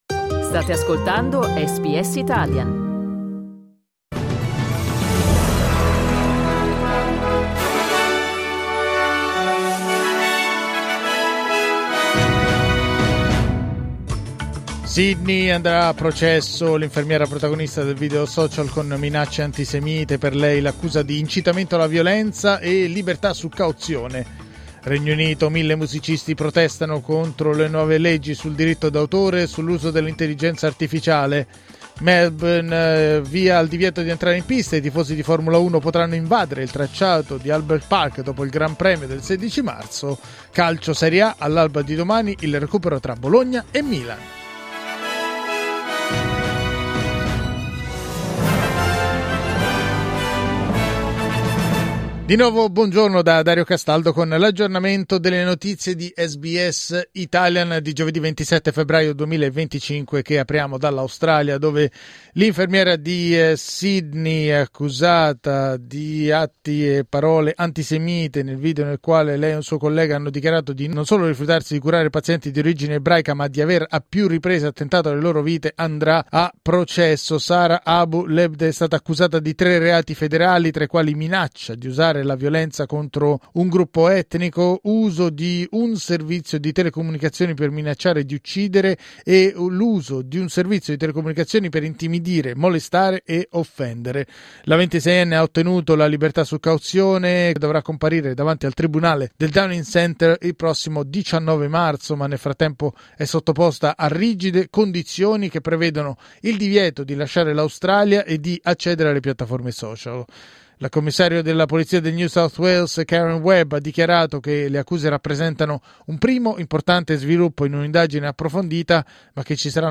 News flash giovedì 27 febbraio 2025